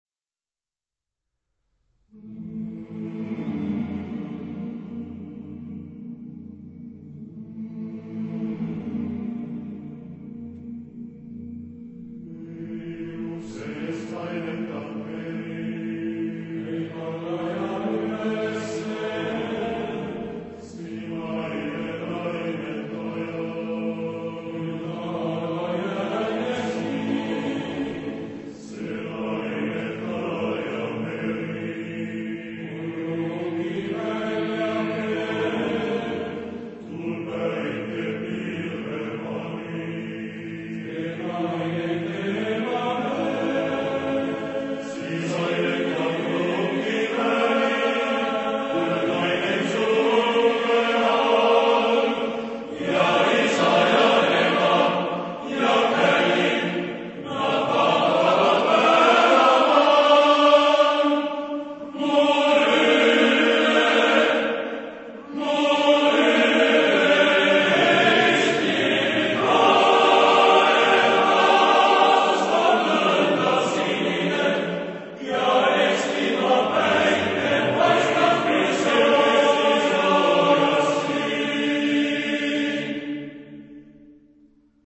Genre-Style-Forme : Profane ; Pièce vocale
Type de choeur : TTTBB  (5 voix égales d'hommes )
Tonalité : la mineur